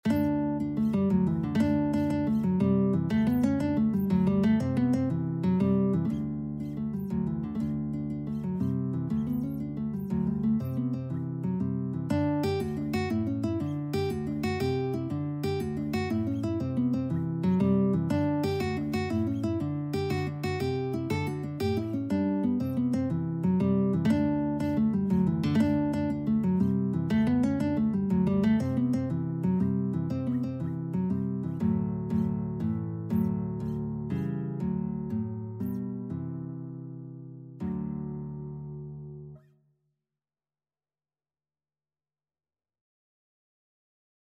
Traditional Trad. A Blast of Wind Guitar version
9/8 (View more 9/8 Music)
D major (Sounding Pitch) (View more D major Music for Guitar )
Presto . = c.120 (View more music marked Presto)
Traditional (View more Traditional Guitar Music)
Irish
blast_of_wind_GT.mp3